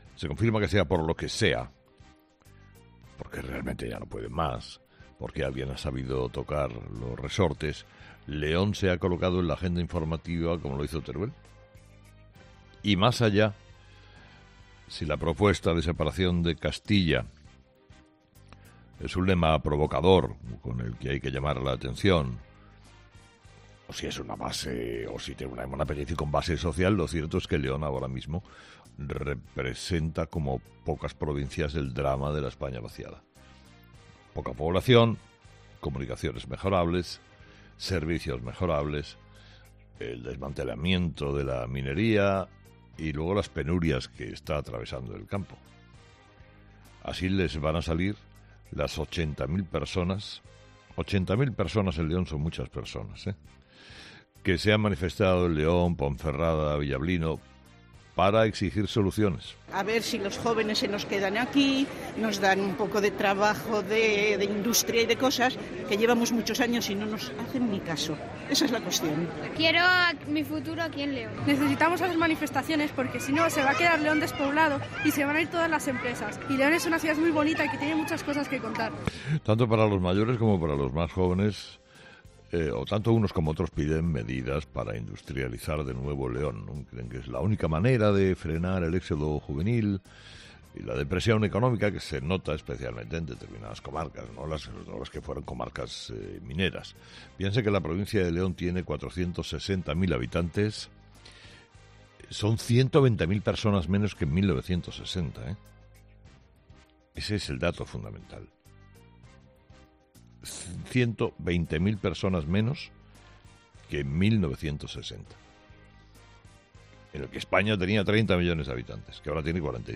Sin duda, una de las principales noticias de este domingo en nuestro país y que no ha querido pasar por alto Carlos Herrera en su monólogo de las 06.00. El comunicador ha analizado los motivos de estas protestas, la situación que vive la provincia y los preocupantes datos de despoblación que presenta: